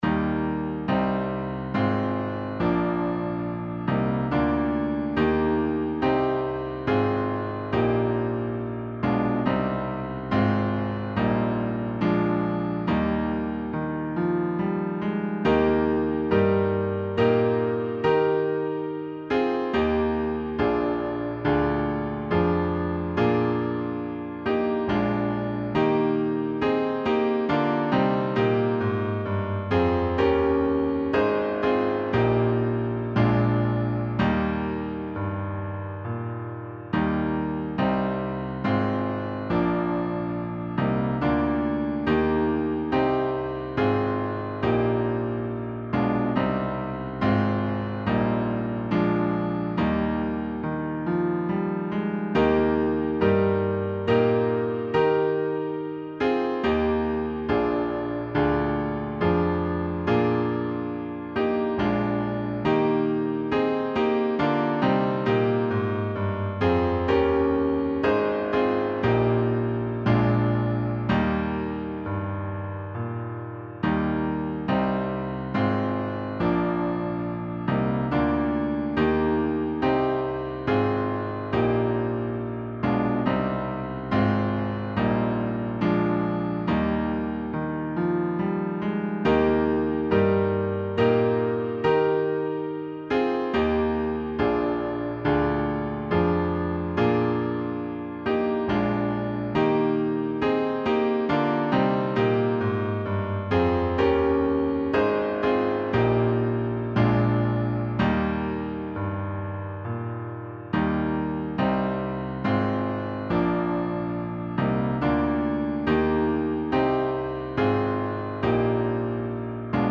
Download this instrumental song for free